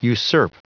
Prononciation du mot usurp en anglais (fichier audio)
Prononciation du mot : usurp